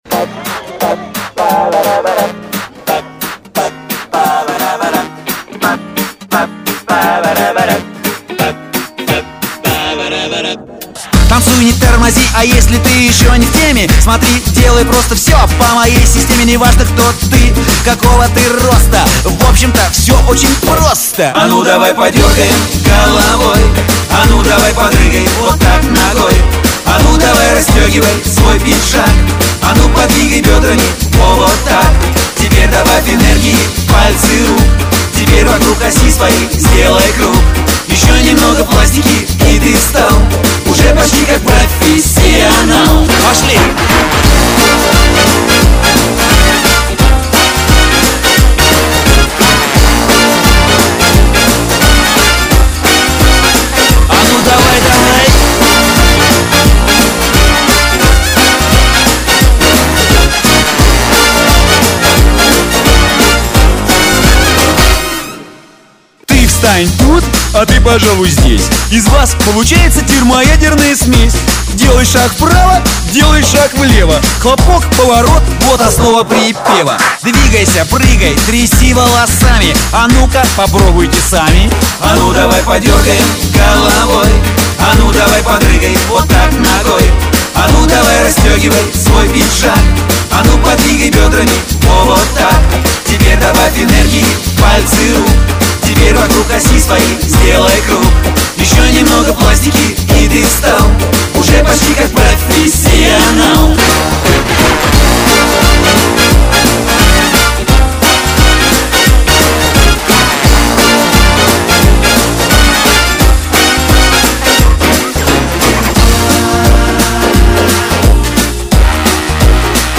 танцевальная музыка для флэш-моба